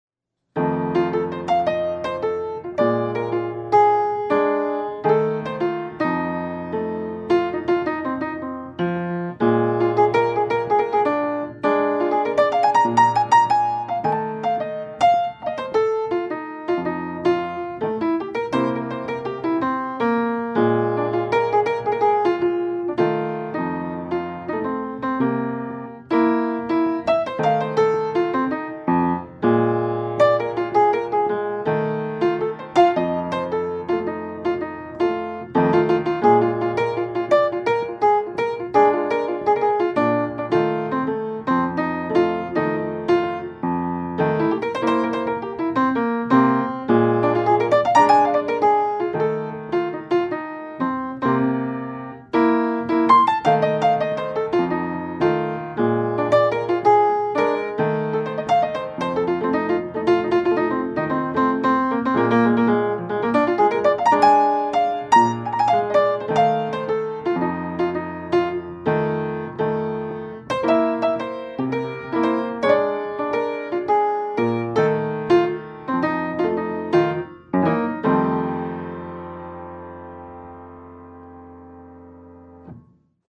Jazzitalia - Lezioni - Piano, Musica Jazz: Blues con note dell'accordo
Es in F:
Dovrete per forza giocare sul ritmo, pronuncia, swing per poterlo fare.